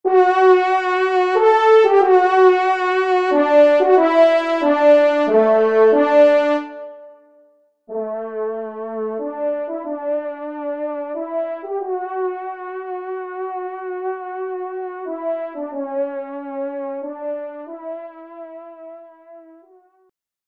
Genre : Fantaisie Liturgique pour quatre trompes
Pupitre 1° Trompe